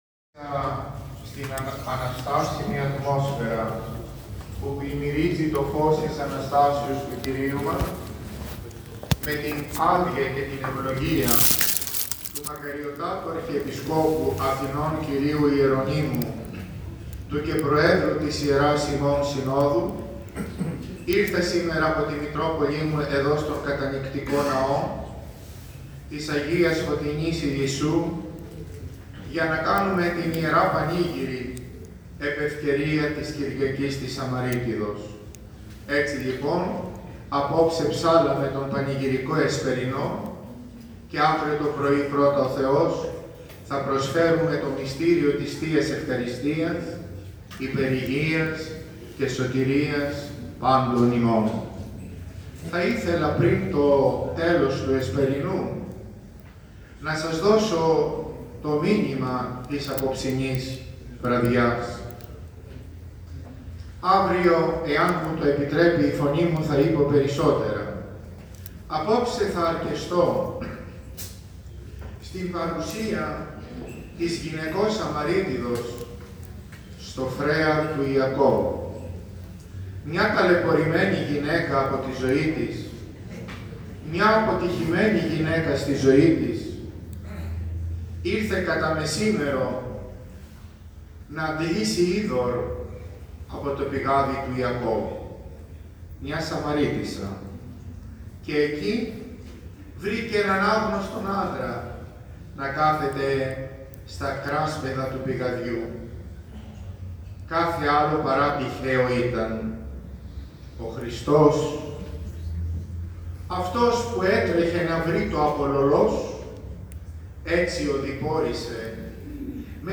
Τελέστηκε, σήμερα, 25 Μαίου 2019, ο Μέγας Εσπερινος της Εορτής της Αγίας Φωτεινής της Σαμαρείτιδος στον ομώνυμο ιερό ναό του Ιλισσού. Στον εσπερινό χοροστάτησε ο Σεβ. Μητροπολίτης Περιστερίου, κ. Κλήμης.
Στο μεταξύ, εν γένει, αλλά και σε προσωπικό εξομολογητικό τόνο, είπε τα εξής: